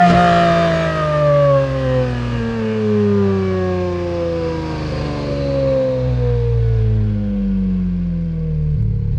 rr3-assets/files/.depot/audio/Vehicles/v12_06/v12_06_decel.wav
v12_06_decel.wav